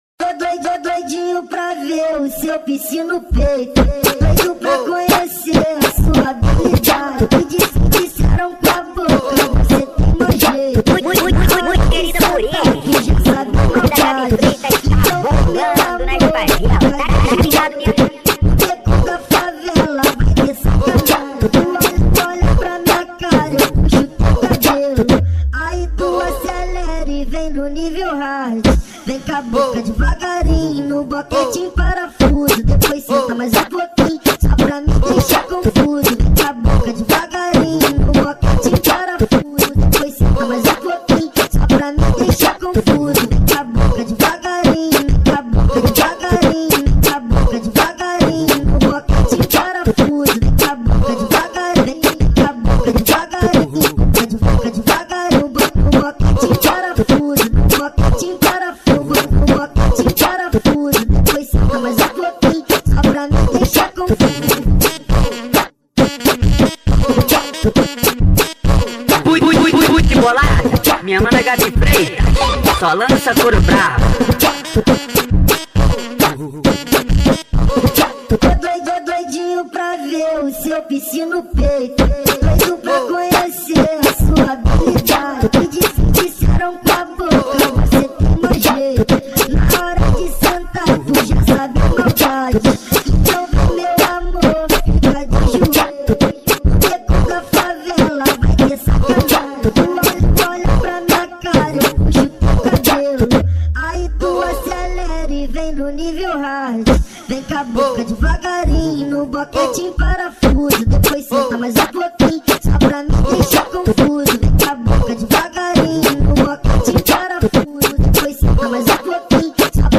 2024-10-25 17:04:42 Gênero: Funk Views